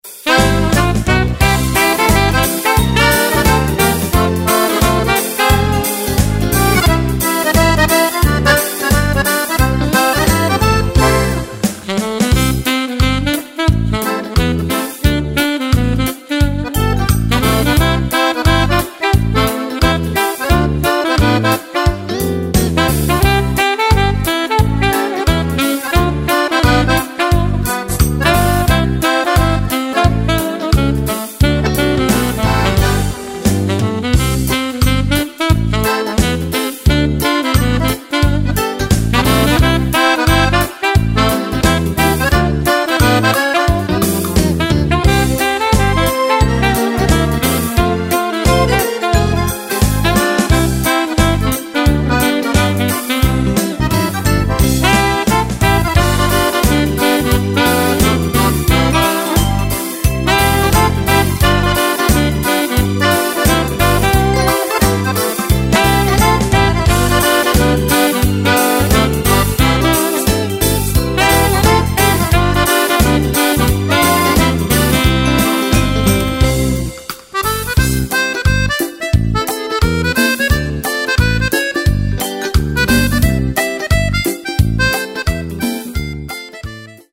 Fox country
Sax